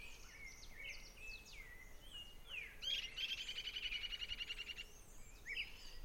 Maria-cavaleira (Myiarchus ferox)
Nome em Inglês: Short-crested Flycatcher
Fase da vida: Adulto
Localidade ou área protegida: Parque Nacional Pre-Delta
Condição: Selvagem
Certeza: Fotografado, Gravado Vocal